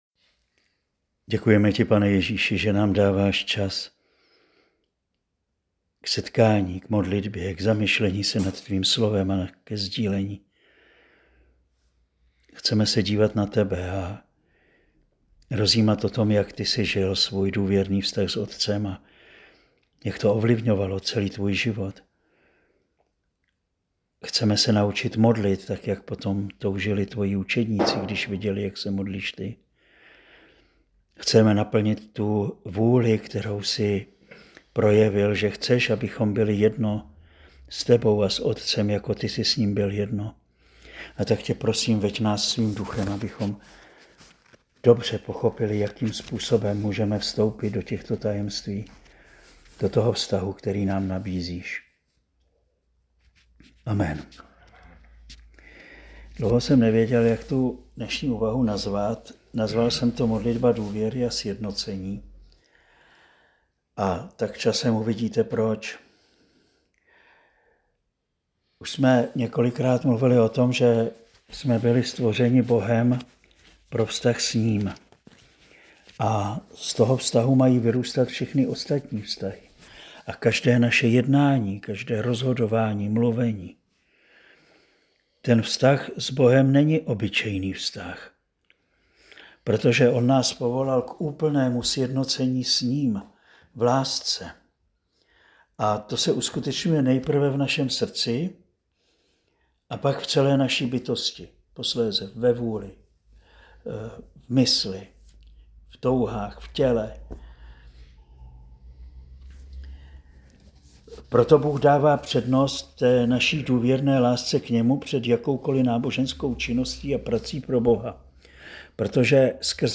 Záznam přednášky, která zazněla v červnu 2025